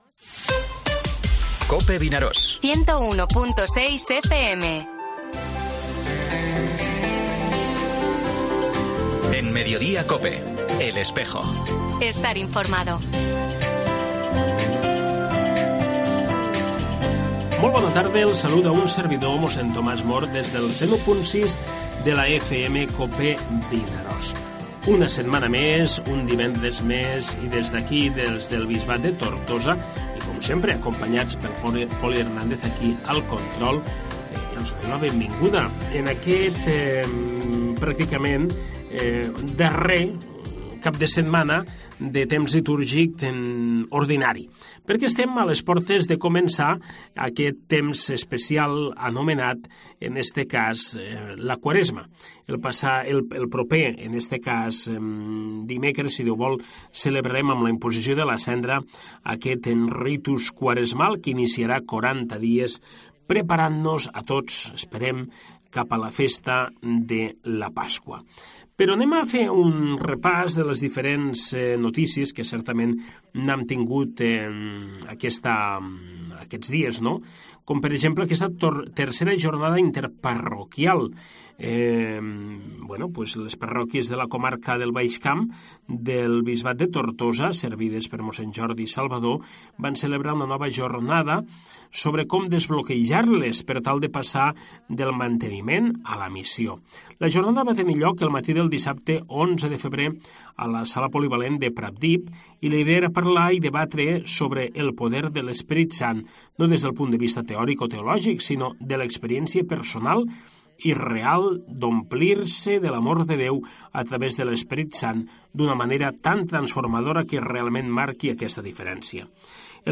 AUDIO: Programa del Bisbat de Tortosa.